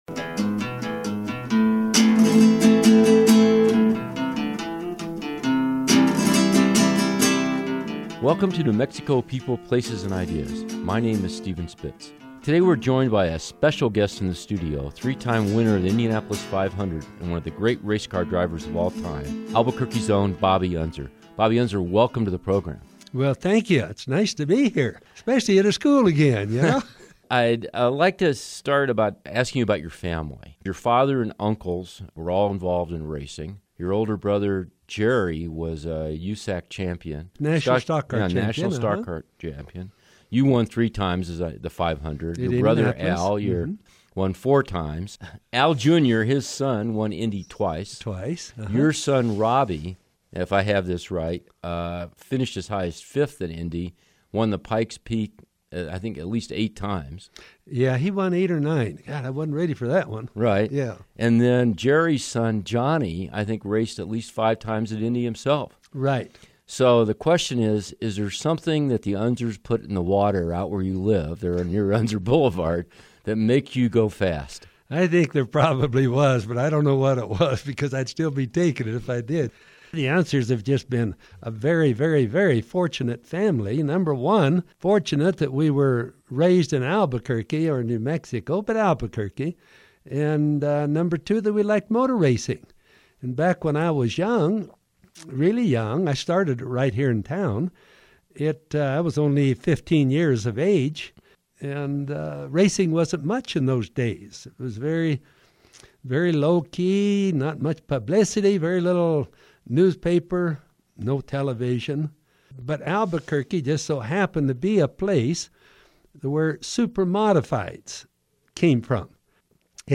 This month’s guest is Bobby Unser, three-time winner of the Indianapolis 500 and the older brother of four-time Indy winner, Al Unser, Sr., who founded the Unser Racing Museum in Albuquerque.
If you are interested in what it is like to first take the track at Indianapolis at speeds over 200 mph, while the owner of a million-dollar car nervously watches a rookie driver immediately go for top speeds, then you must hear Bobby Unser tell the story of his initial attempts to qualify for the 500.